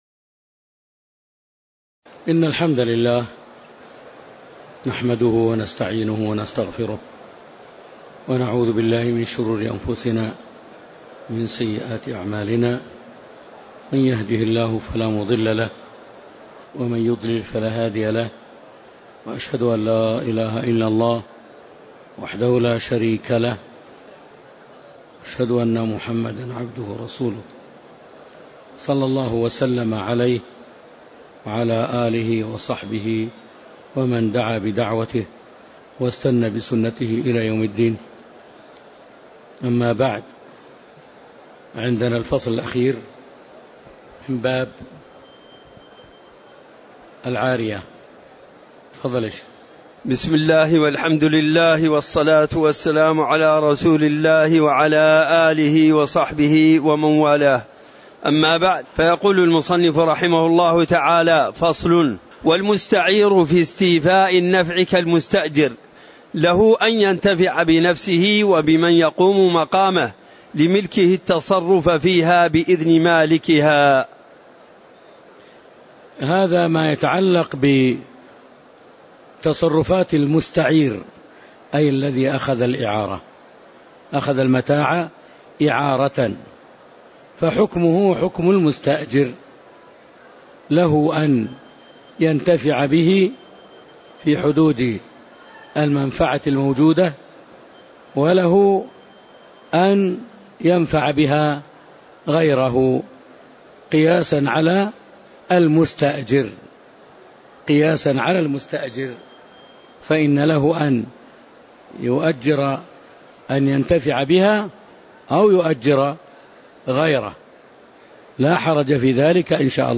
تاريخ النشر ١٤ رمضان ١٤٤٣ هـ المكان: المسجد النبوي الشيخ